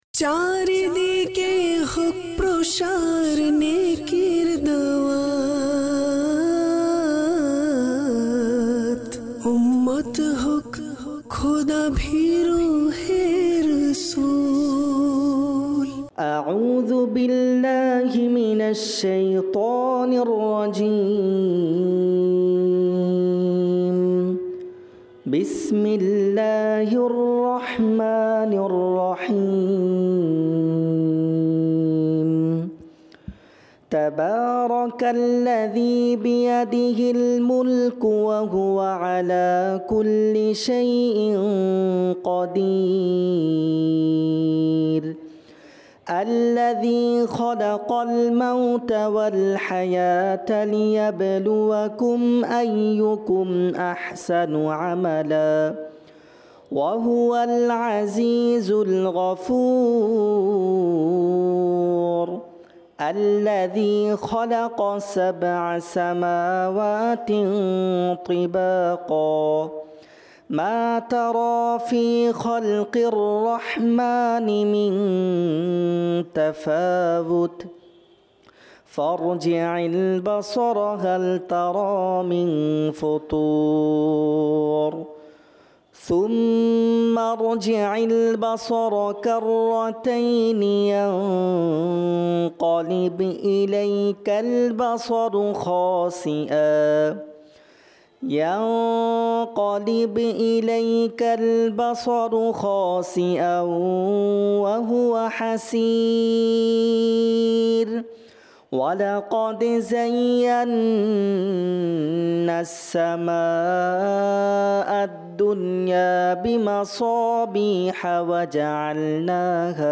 সাপ্তাহিক সুন্নাতে ভরা ইজতিমা EP# 462